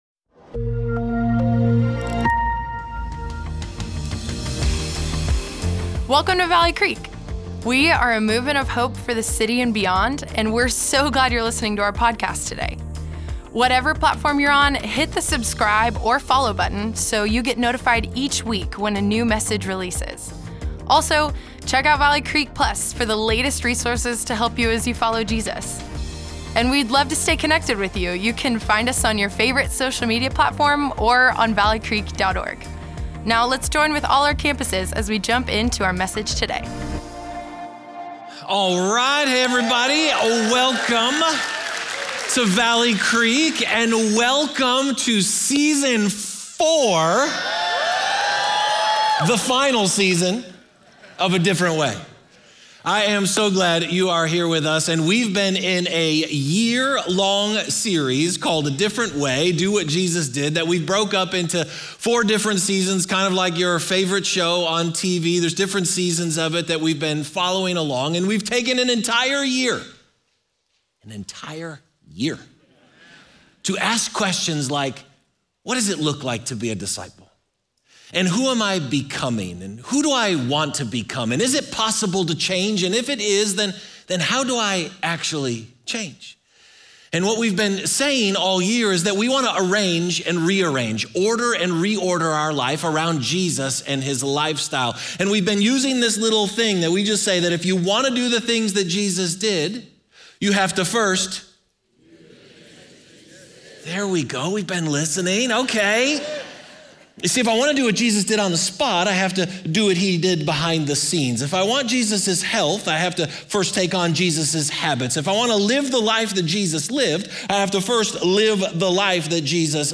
Valley Creek Church Weekend Messages Community Oct 20 2024 | 00:55:09 1x 00:00 / 00:55:09 Subscribe Share Apple Podcasts Spotify Amazon Music Overcast RSS Feed Share Link Embed